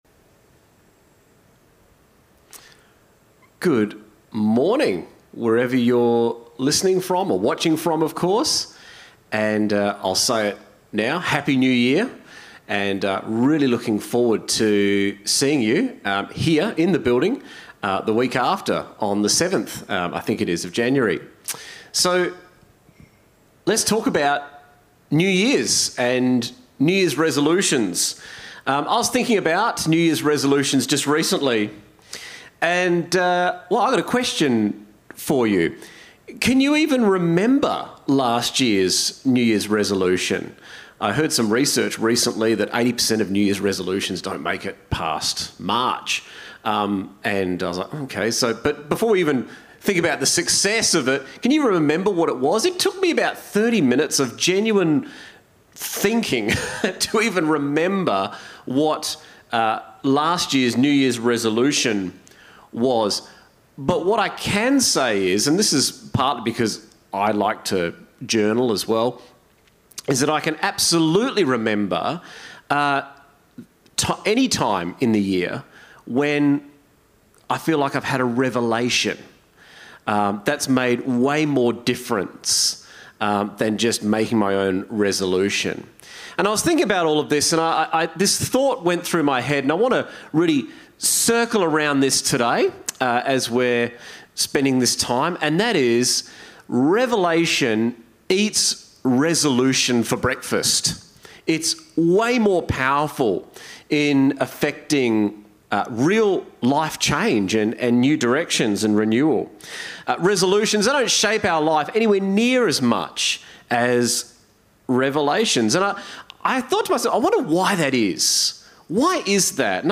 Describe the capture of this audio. Our last service of the year was live-streamed on New Years Day.